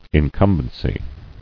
[in·cum·ben·cy]